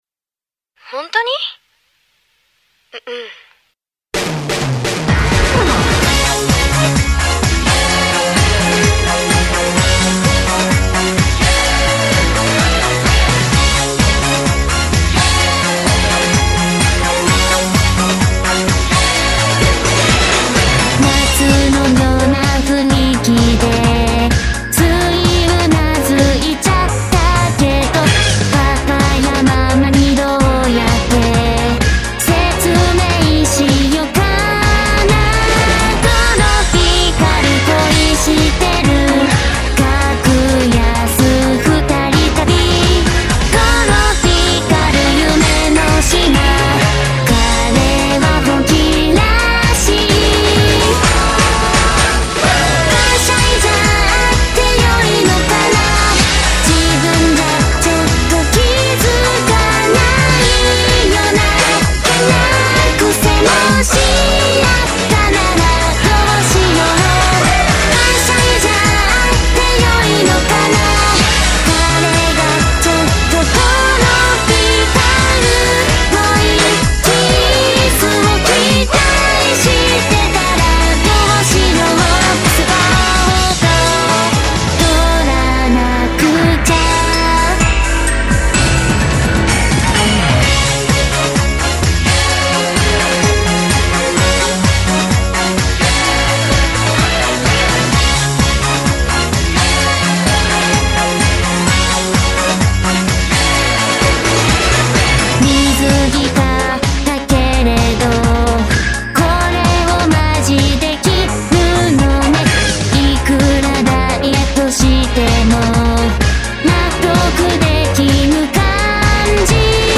オケは今回はオリジナルを使わせていただきました。